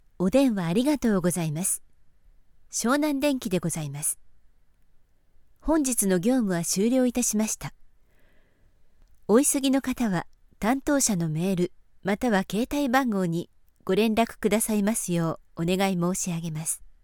Commercieel, Veelzijdig, Vertrouwd, Zakelijk, Jong
Telefonie